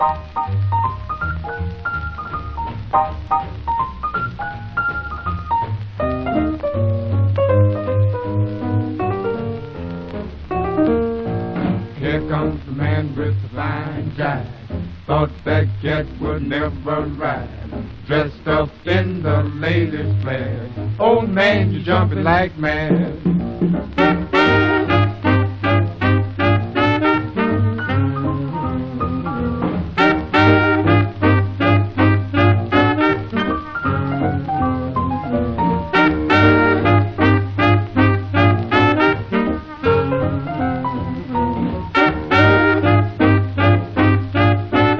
Jazz, Jump Blues, Swing　US　12inchレコード　33rpm　Mono